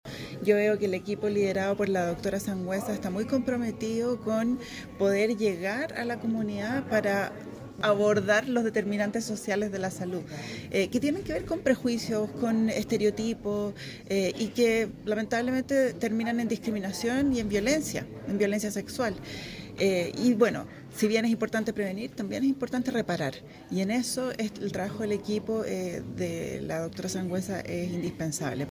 En la instancia, también participó la SEREMI de la Mujer y Equidad de Género, María Fernanda Glaser, quien destacó que
Maria-Fernanda-Glaser-SEREMI-de-la-mujer-y-equidad-de-genero-Coquimbo.mp3